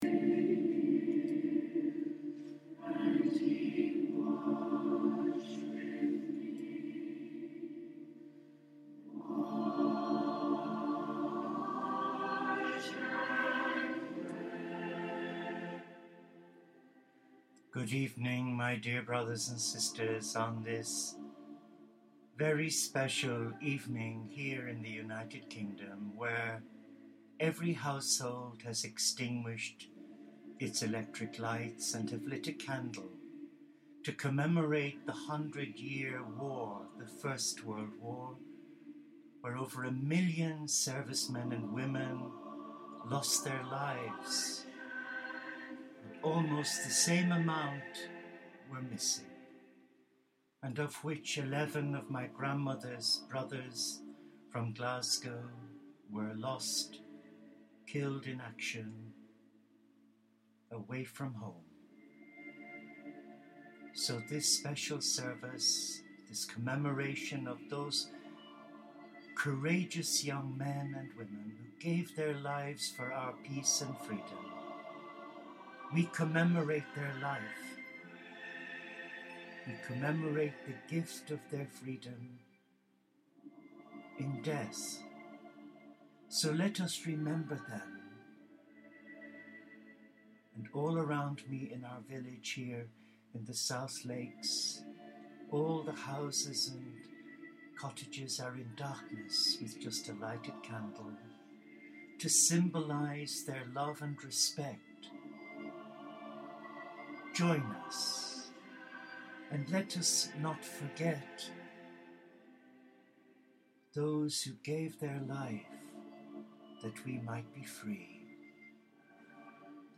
Tonight's podcast is one of silent reflection with Taize chants and the occasional reading and prayer.